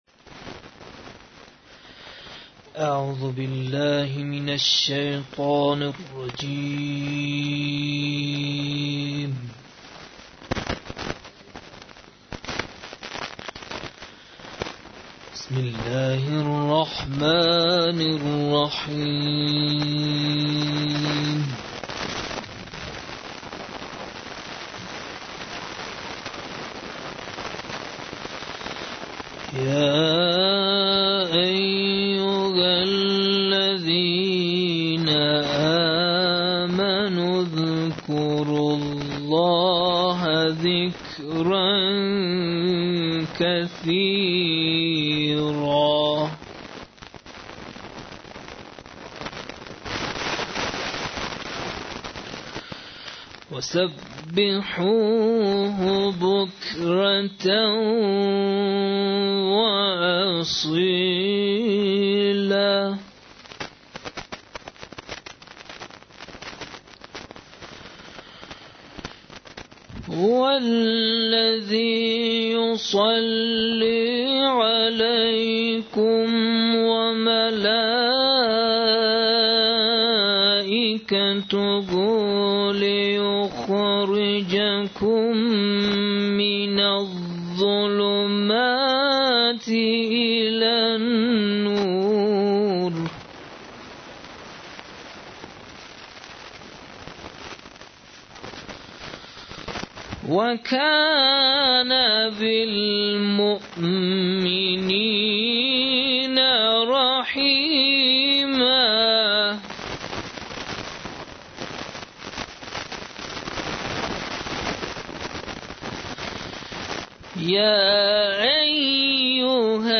قرائت قرآن مجید